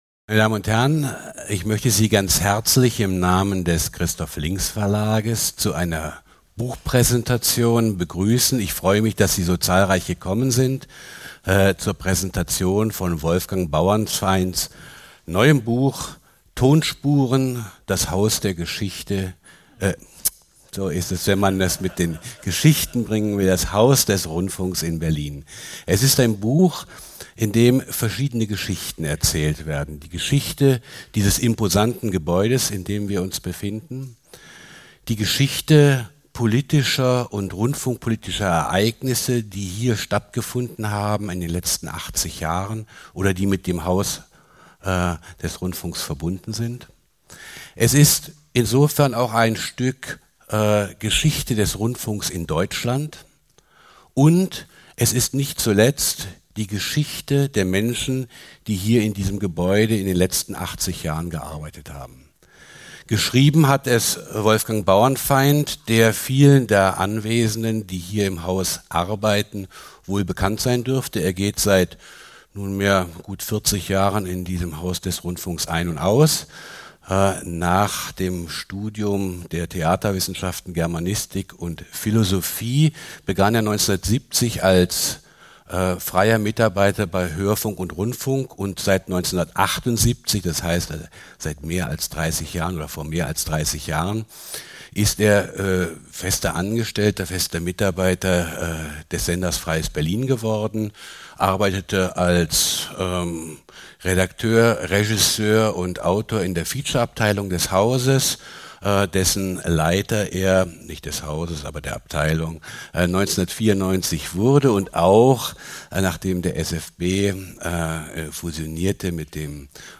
Kleiner Sendesaal, Haus des Rundfunks, rbb, Berlin Wann